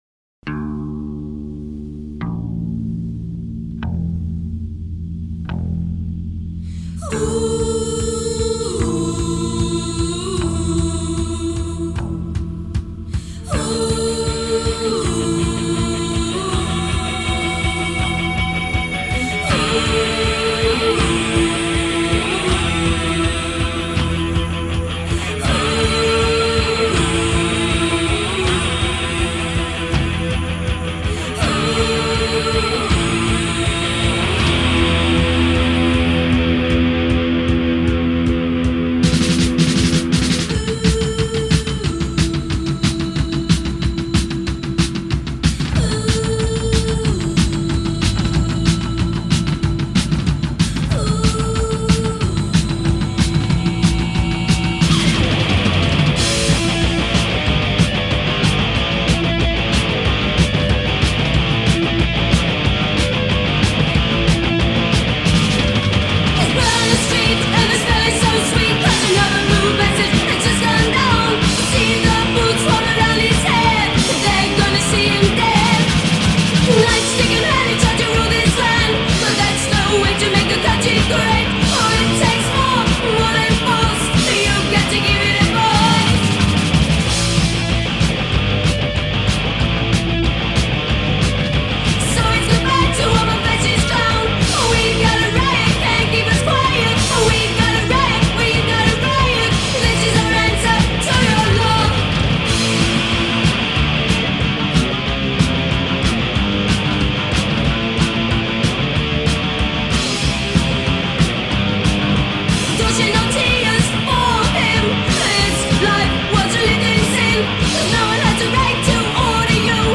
a mostly forgotten but incredible British punk band